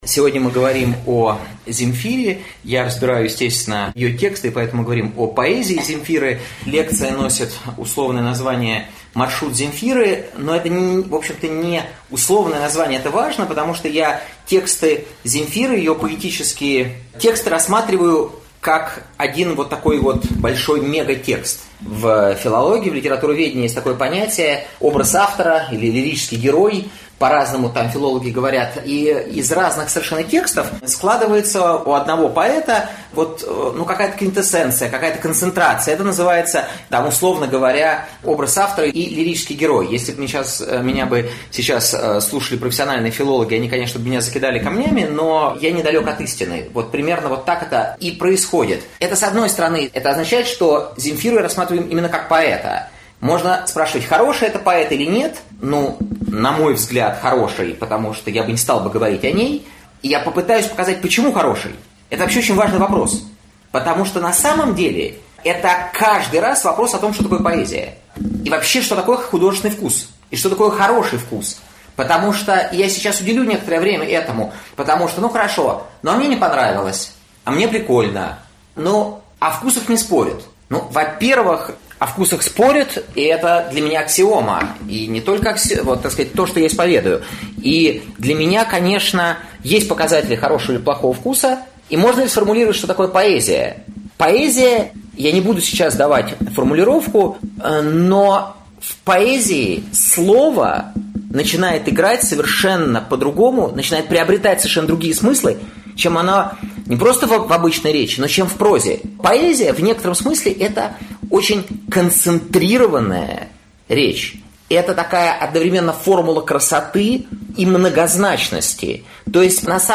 Аудиокнига Маршрут Земфиры | Библиотека аудиокниг